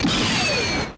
raygun.ogg